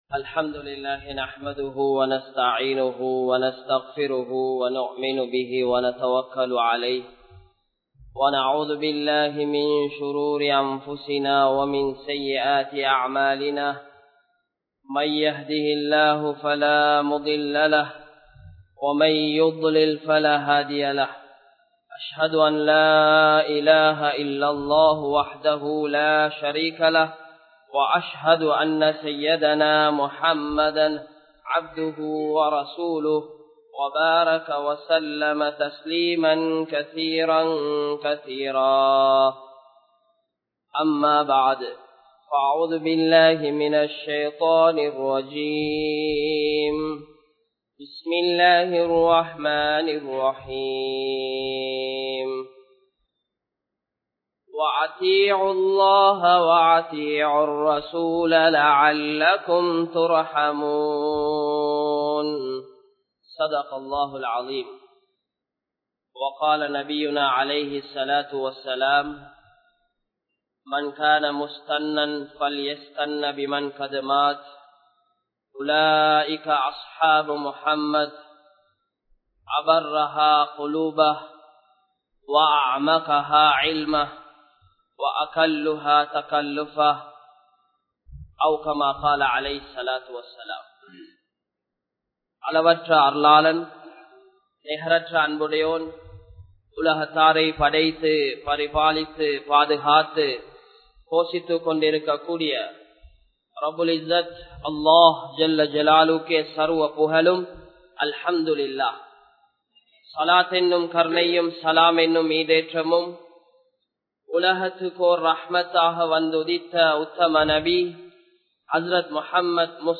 Nabi Valiyai Pin Pattrungal (நபி வழியை பின்பற்றுங்கள்) | Audio Bayans | All Ceylon Muslim Youth Community | Addalaichenai